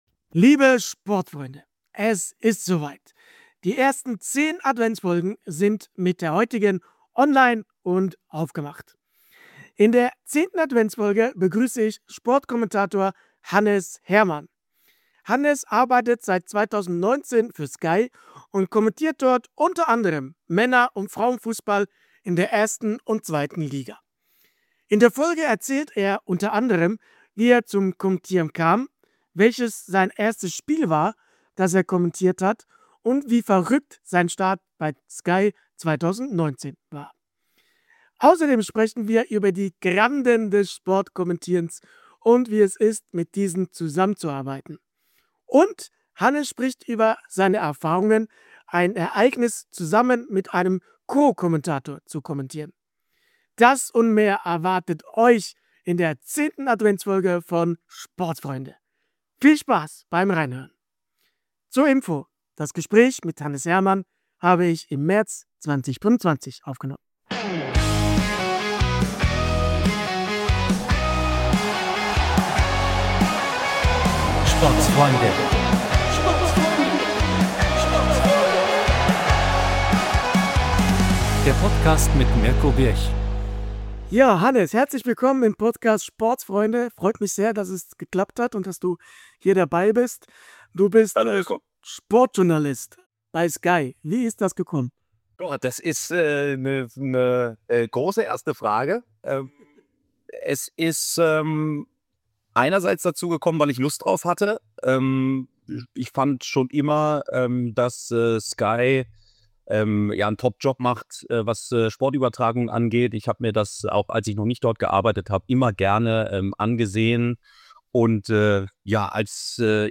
Ein spannendes Gespräch über Konzentration, Leidenschaft und die Magie ...